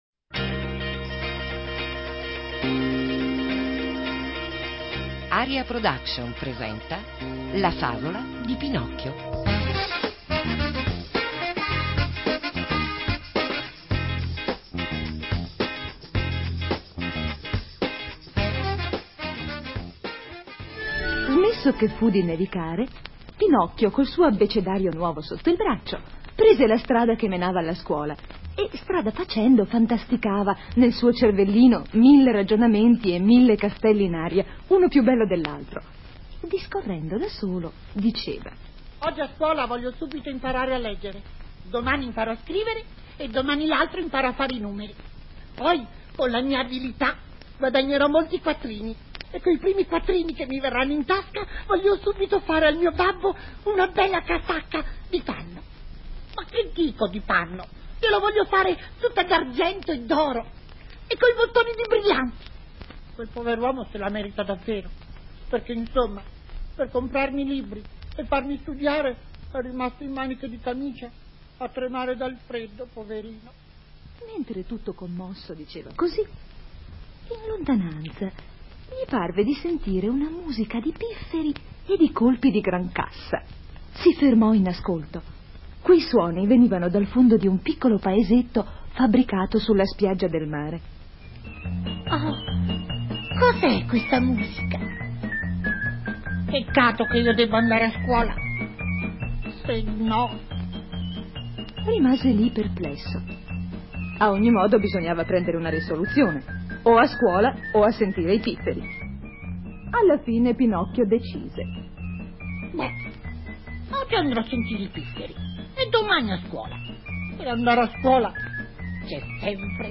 Attori, rumori, musiche, una radiofavola in piena regola in 48 puntate che evidentemente è stata distribuita in network.
Alcune radio in Italia l'hanno trasmessa, è il segno che all'epoca anche il mondo dell'infanzia era tenuto in considerazione dalle antenne libere.